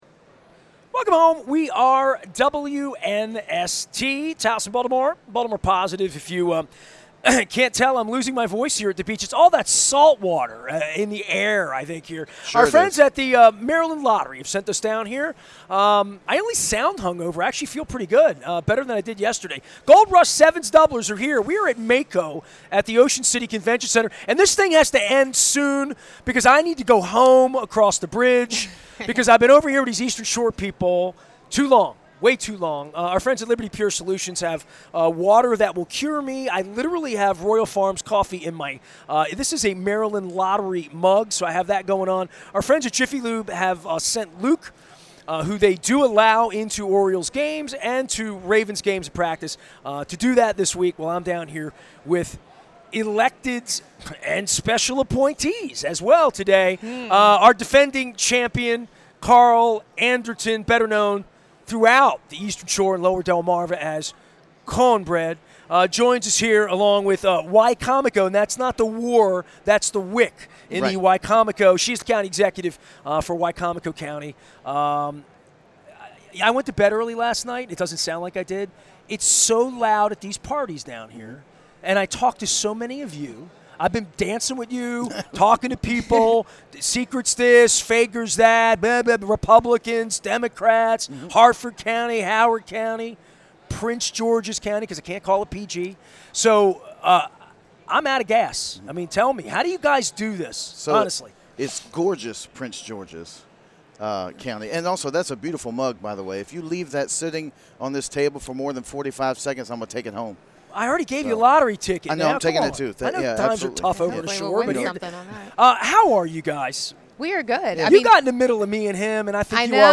Our defending champion and former Delegate Carl Anderton Jr. drops by in his new role as Director of Rural Economic Strategy along with Wicomico County Executive Julie Giordano to discuss life on the Eastern Shore from MACo in Ocean City. (And a little Ravens football, too!)